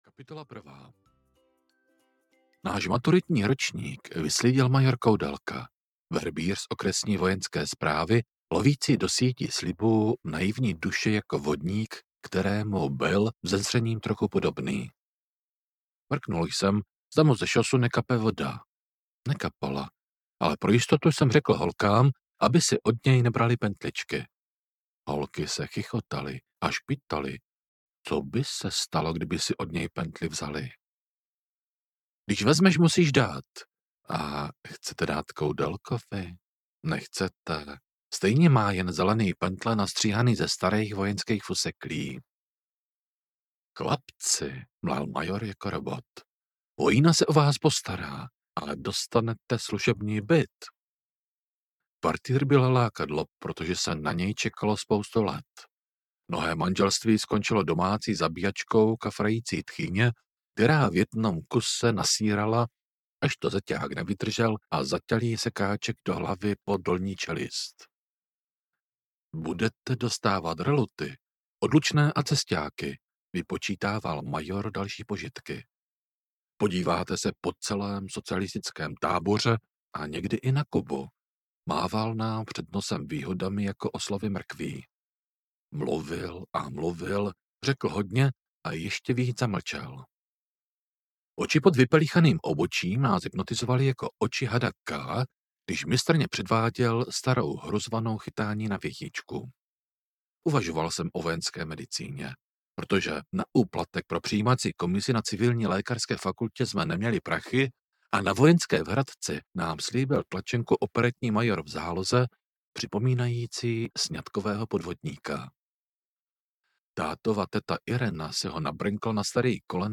Bílí baroni: bílé pláště v zajetí zelených mozků audiokniha
Ukázka z knihy